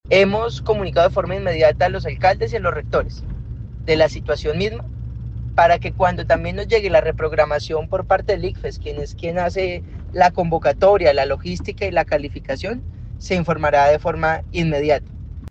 Nicolás Ordóñez, secretario de Educación de Santander